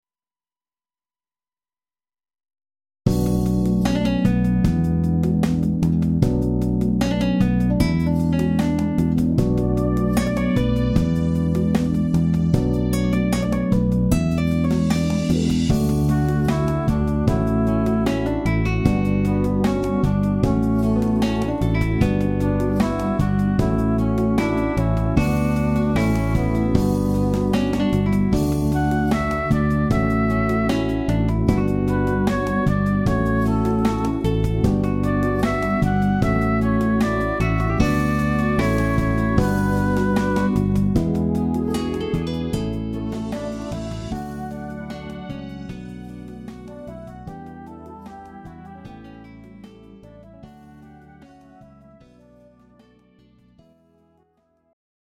Greek Rock Ballad